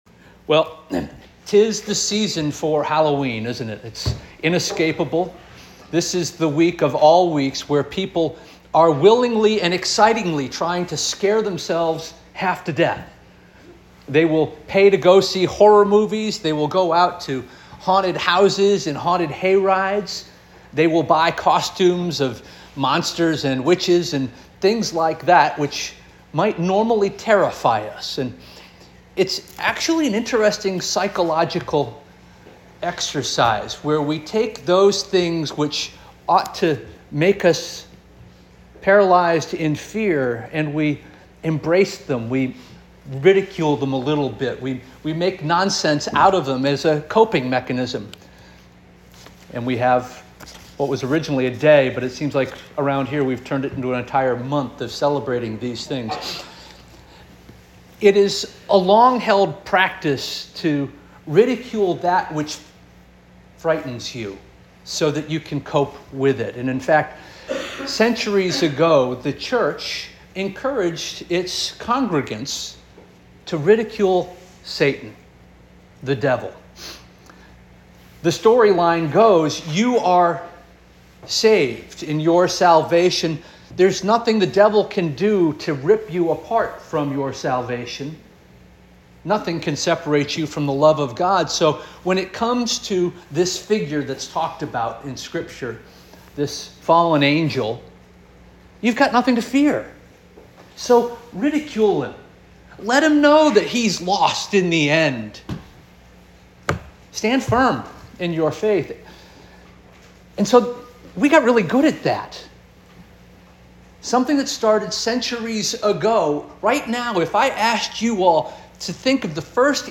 October 27 2024 Sermon - First Union African Baptist Church